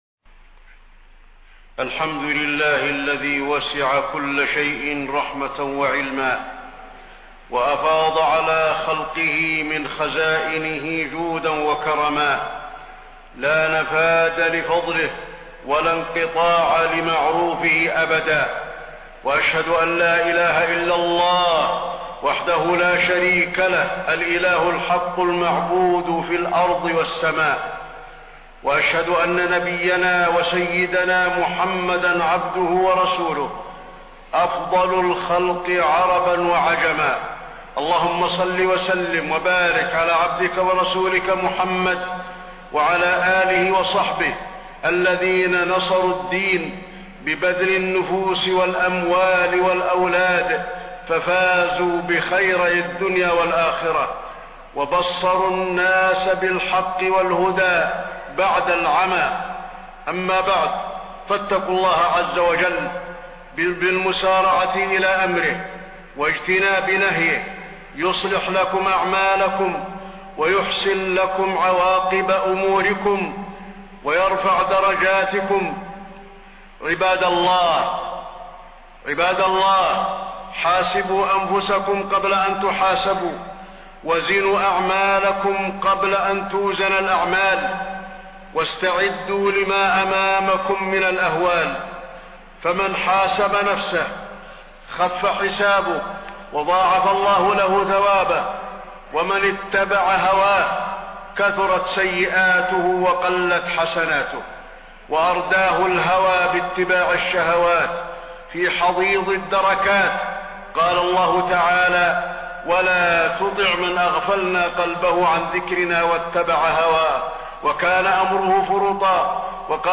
تاريخ النشر ٢٥ شوال ١٤٣٢ هـ المكان: المسجد النبوي الشيخ: فضيلة الشيخ د. علي بن عبدالرحمن الحذيفي فضيلة الشيخ د. علي بن عبدالرحمن الحذيفي طرق إصلاح الأمة عند الفتن